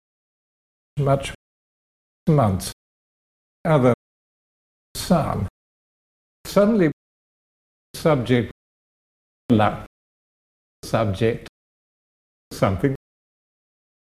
Listen to the brighter [a]-like STRUT by RP15 (Figure 2):